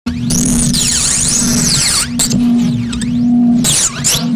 A segment of the "Glass Break 2" audio file, with a spatial filter, and a few different audio effects placed onto it. This sound is correlated with the letter "t" on the computer keyboard.